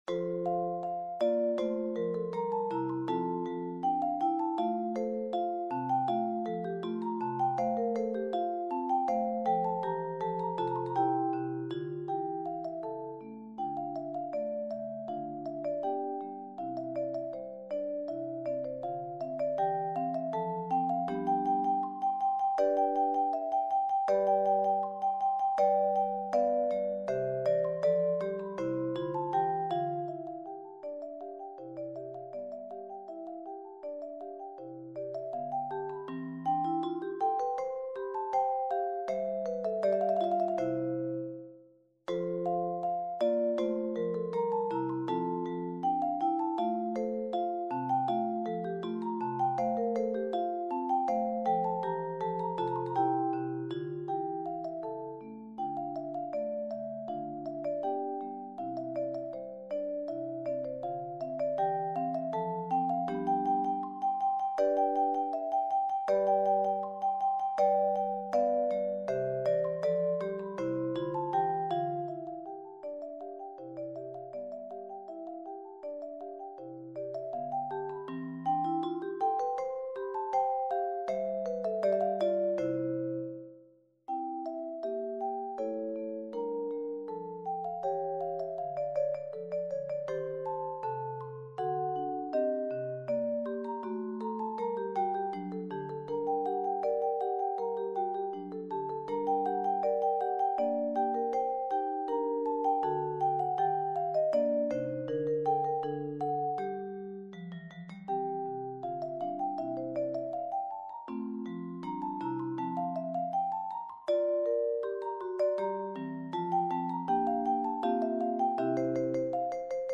rearranged for handbells or handchimes